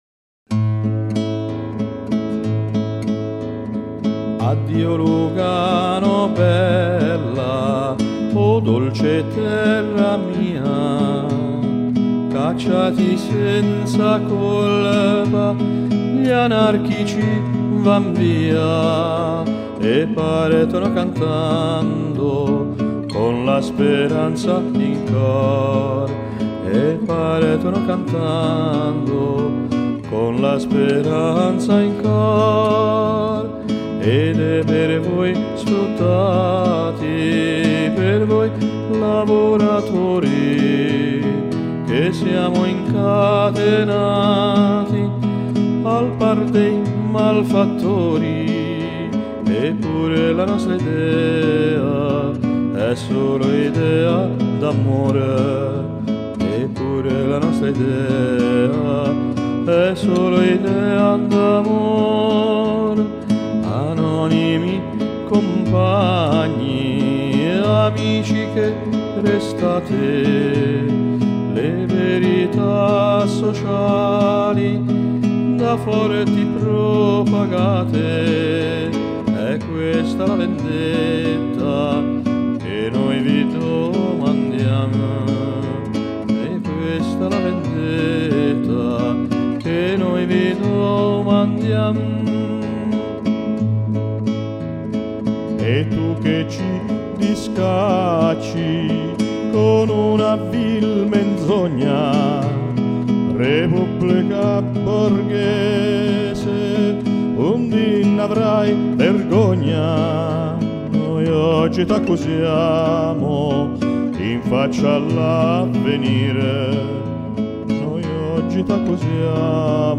Il m’a offert un CD dans lequel il chante les chansons de Pietro Gori (anarchiste italien).